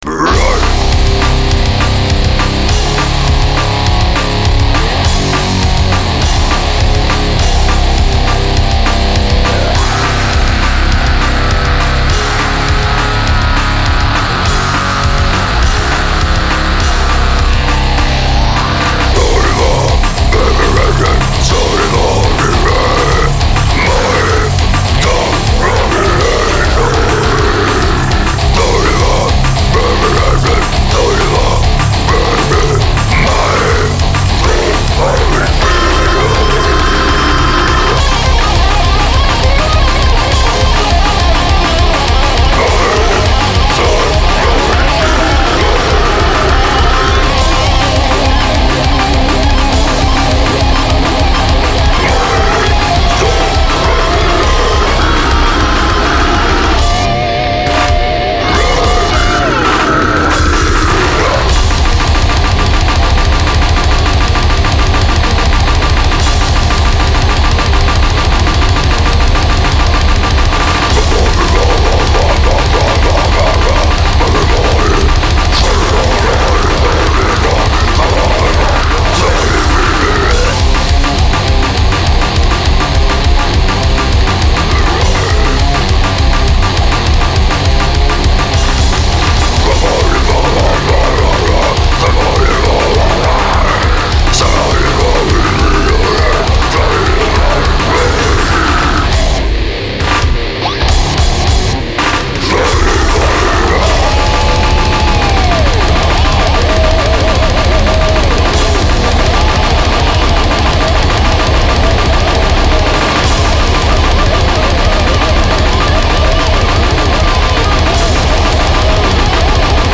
basses, guitars
vocals
drums
lead guitar